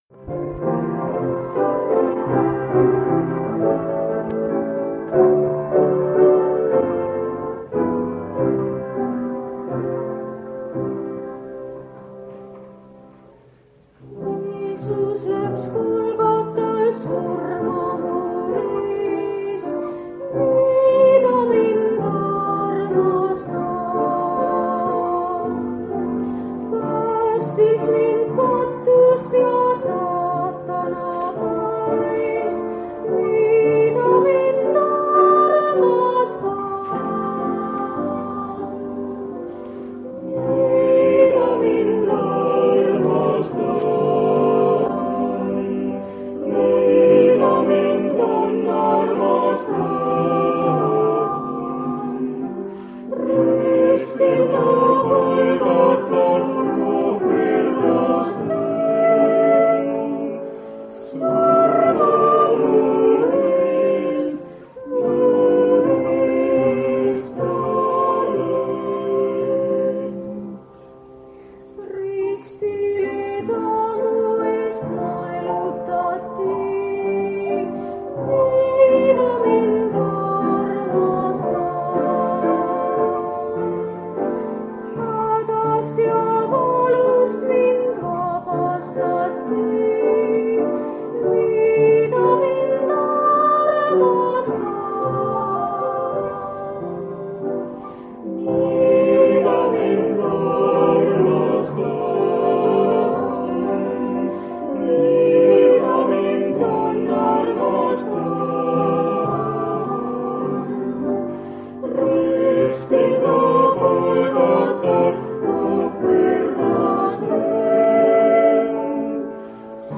Kingissepa linnas Saaremaal (täna Kuressaares) toimub EVANGEELIUMINÄDAL.